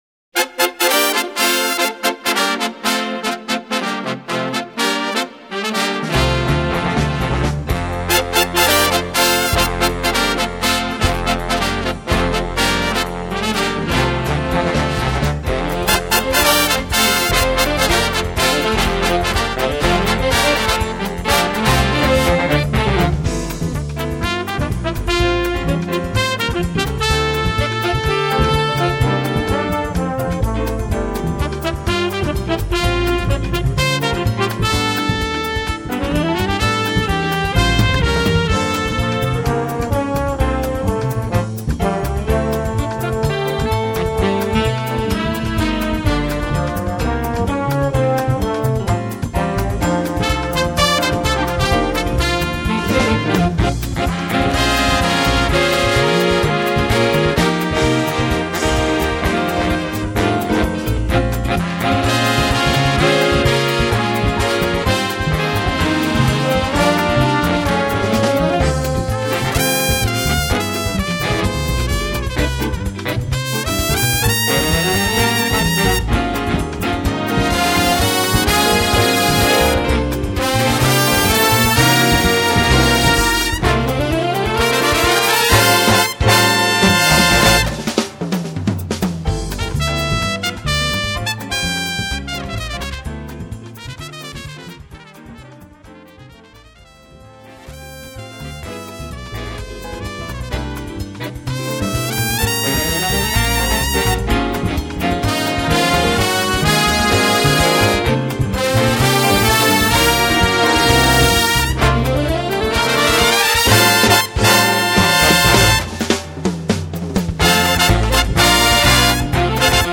Voicing: Score and Parts